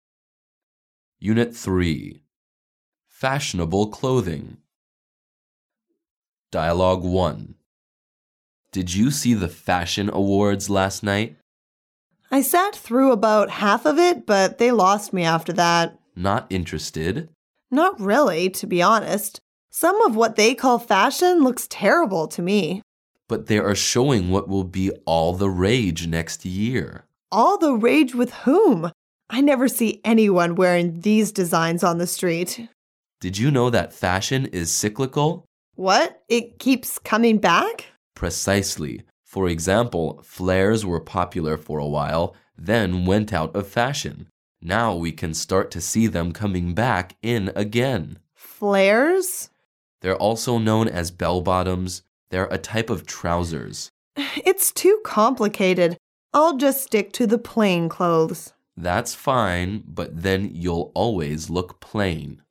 Dialouge 1